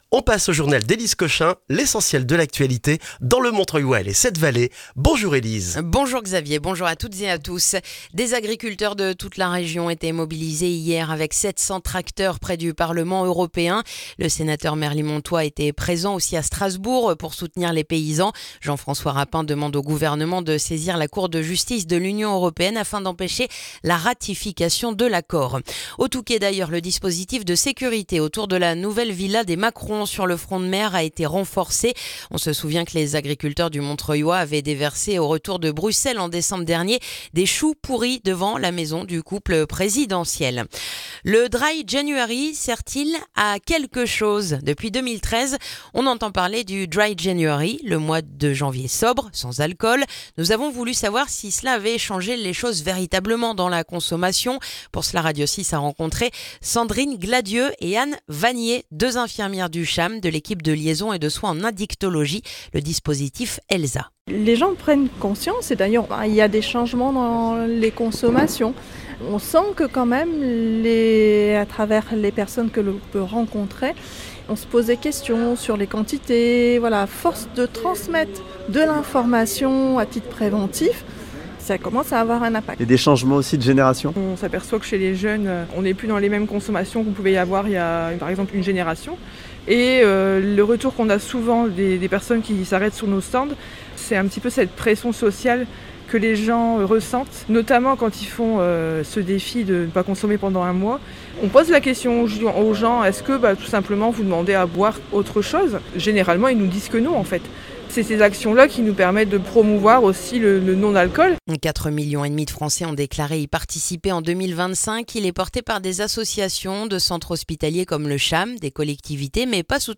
Le journal du mercredi 21 janvier dans le montreuillois